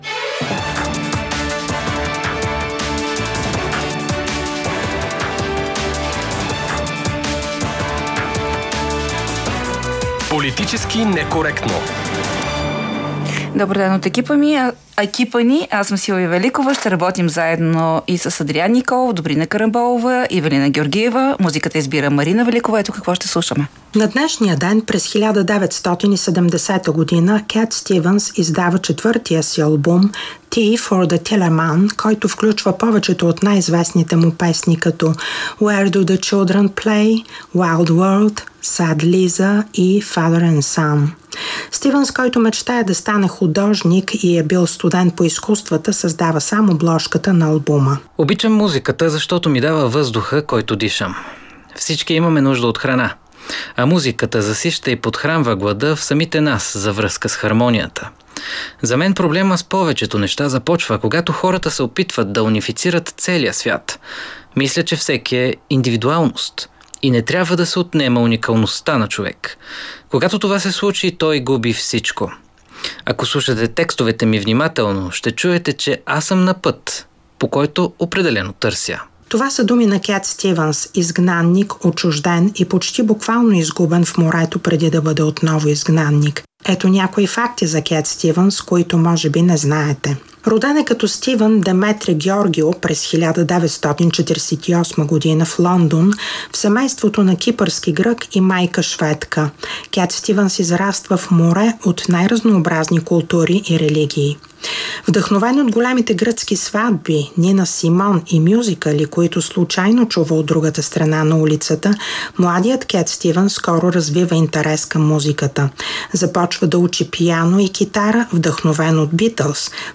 ▪ Гост е бившият министър на отбраната Бойко Ноев.